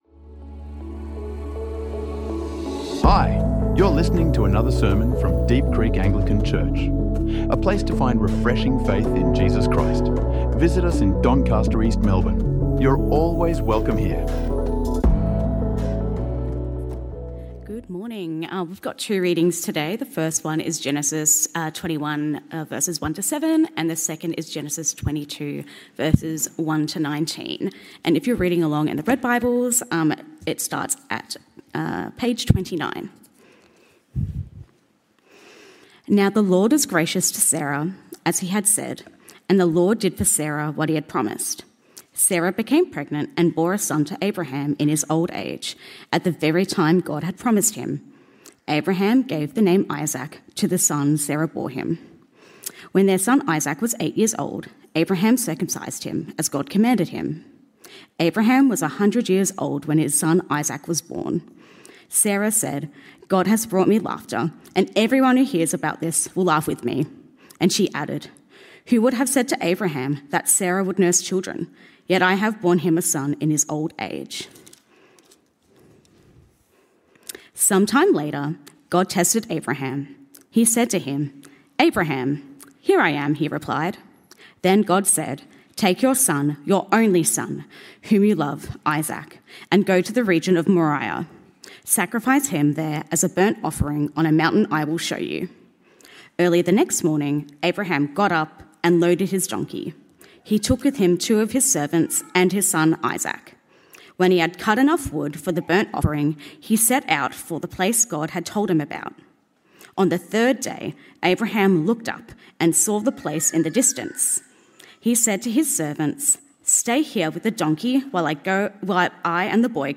This sermon explores the shocking test of Abraham in Genesis 22, revealing a God who provides the ultimate substitute.